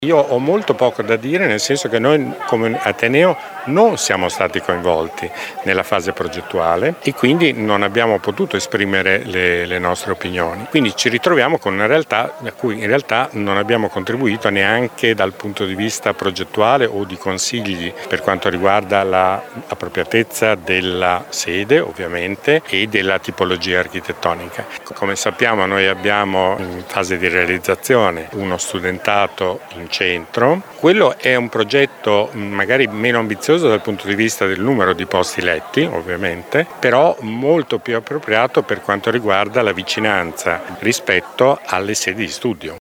Il commento del rettore di Unimore Carlo Porro: